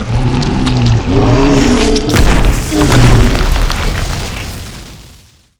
Index of /client_files/Data/sound/monster/dx1/